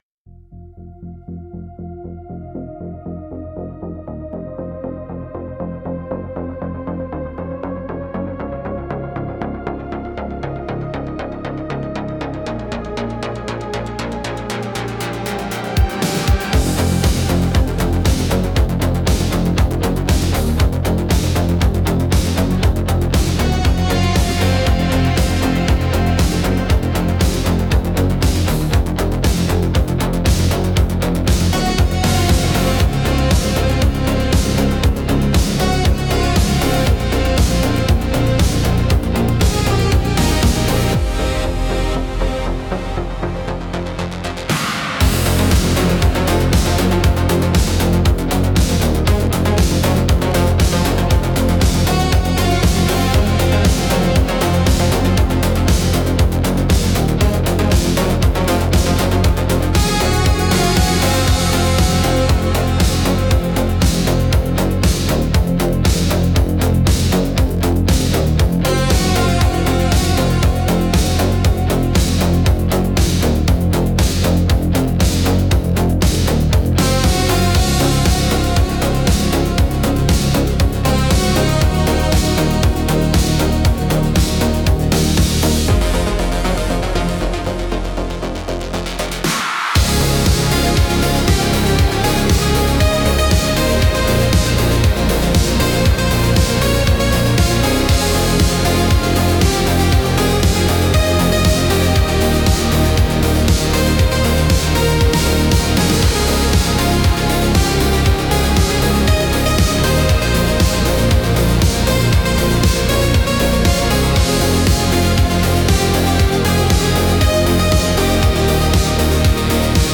Instrumental - Concrete Pulse 4.37